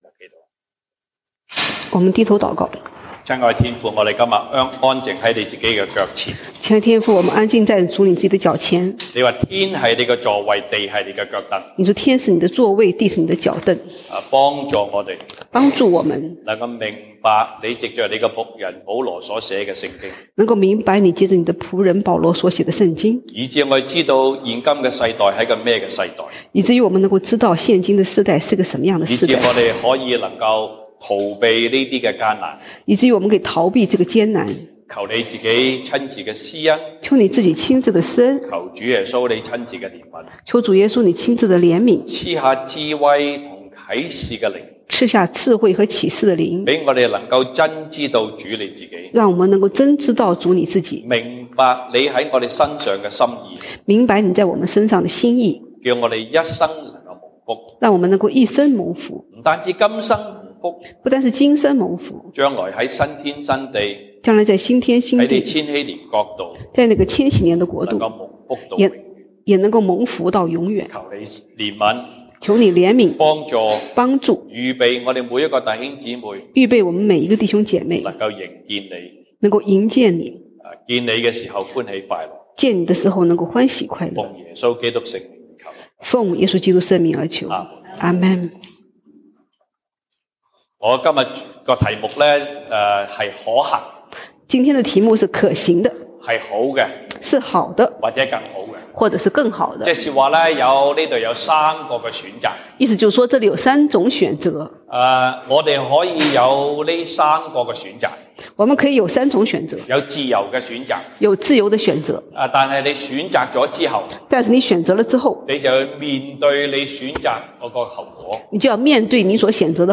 西堂證道(粵語/國語) Sunday Service Chinese: 可行，好的，更好